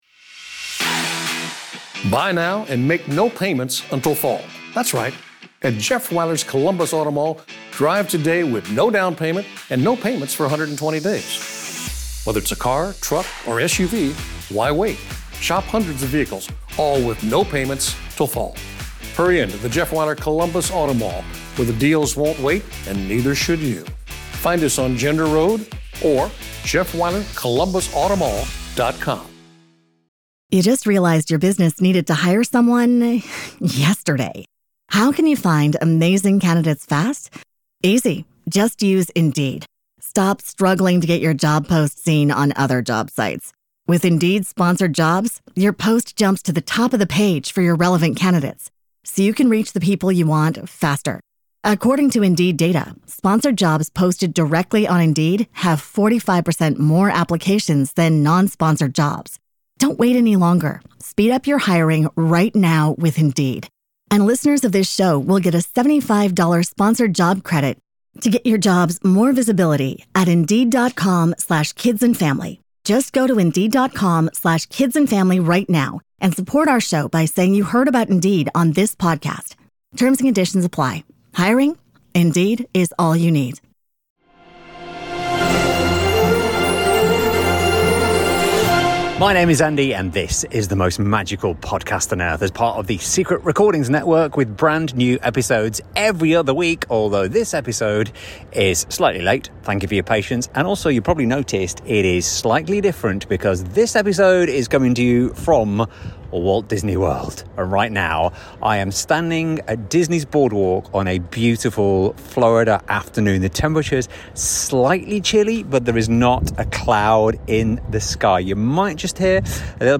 This episode comes from Walt Disney World's Boardwalk resort, with everything you need to know about the holidays in the parks.